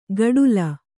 ♪ gaḍula